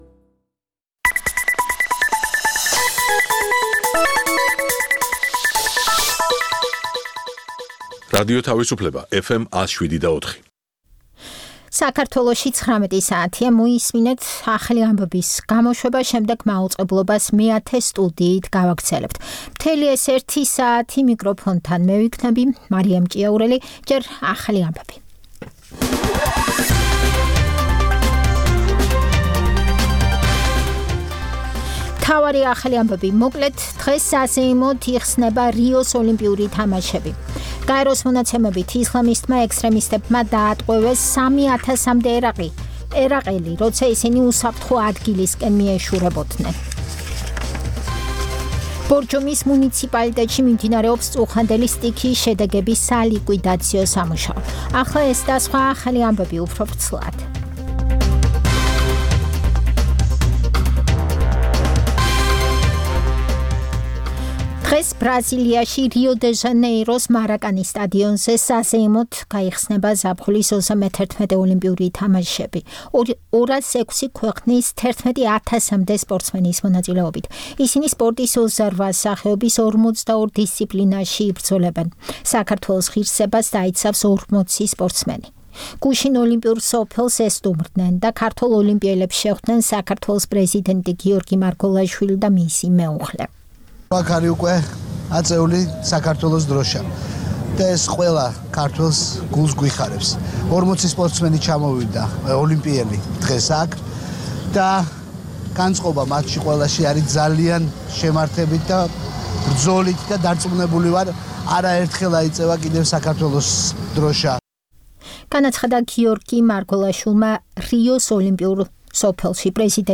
ეს პროგრამა ჩვენი ტრადიციული რადიოჟურნალია, რომელიც ორი ათეული წლის წინათ შეიქმნა ჯერ კიდევ მიუნხენში - რადიო თავისუფლების ყოფილ შტაბ-ბინაში, სადაც ქართული რედაქციის გადაცემების ჩასაწერად მე-10 სტუდია იყო გამოყოფილი. რადიოჟურნალი „მეათე სტუდია“ მრავალფეროვან თემებს ეძღვნება - სიუჟეტებს პოლიტიკასა და ეკონომიკაზე, გამოფენებსა და ფესტივალებზე, ინტერვიუებს ქართველ და უცხოელ ექსპერტებთან და ხელოვანებთან.